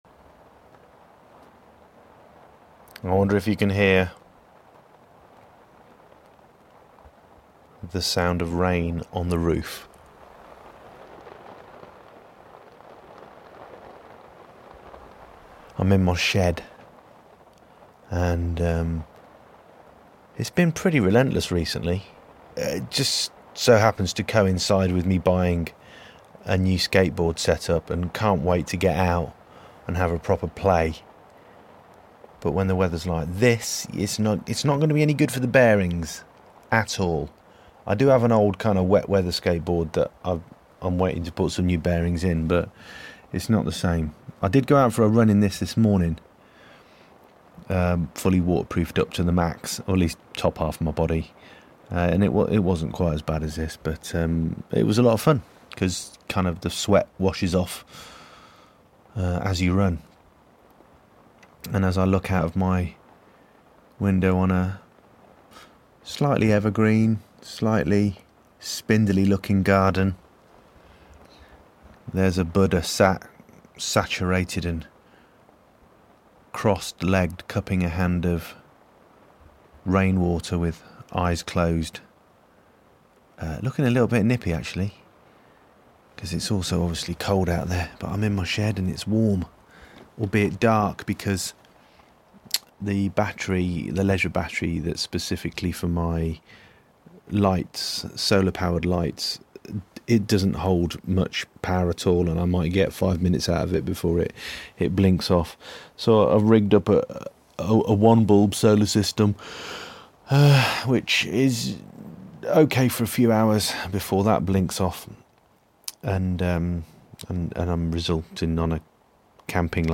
Lethargically pondering the rain on the roof